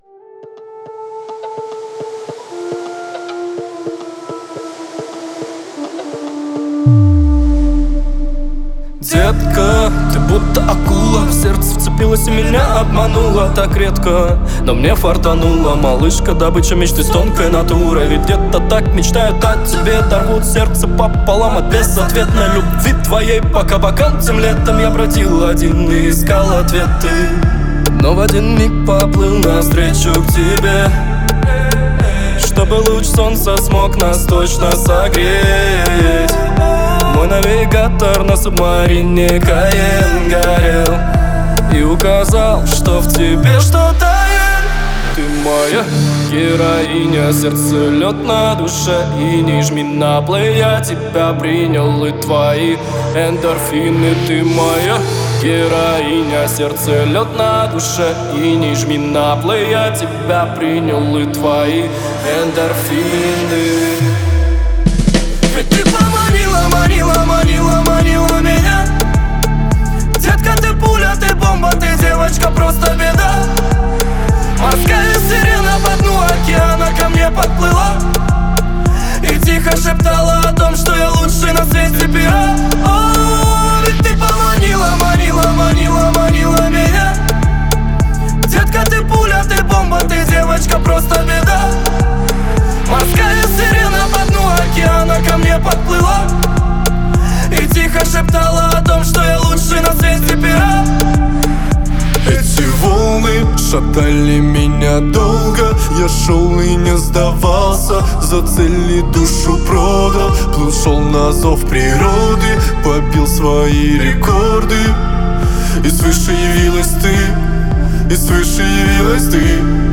это яркая композиция в жанре поп с элементами R&B